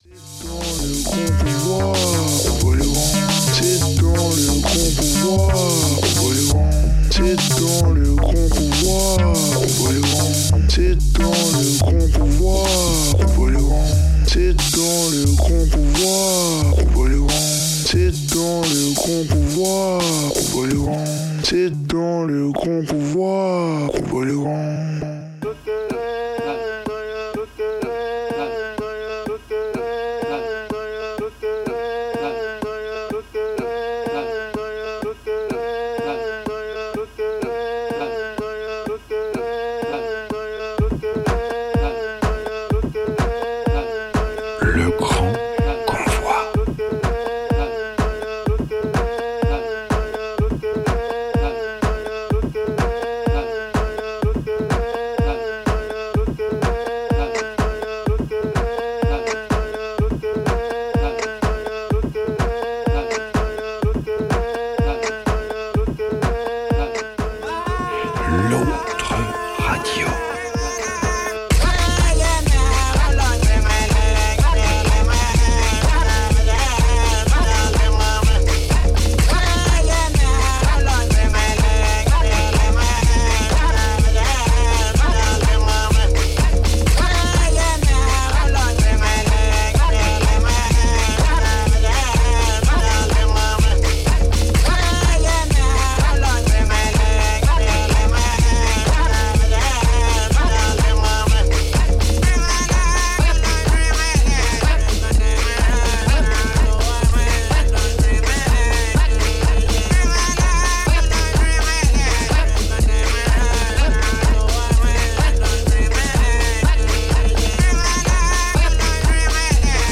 Un set mensuel d'une heure